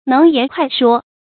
能言快說 注音： ㄣㄥˊ ㄧㄢˊ ㄎㄨㄞˋ ㄕㄨㄛ 讀音讀法： 意思解釋： 見「能言快語」。